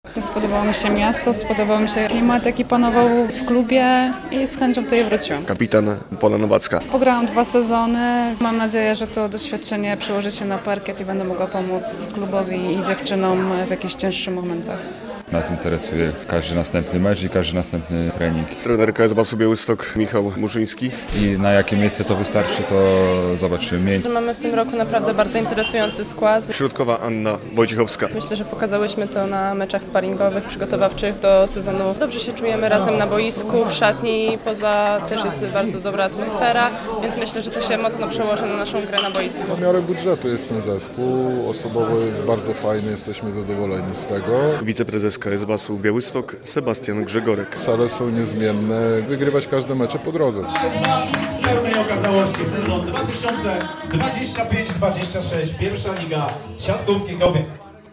Siatkarki BAS przed sezonem - relacja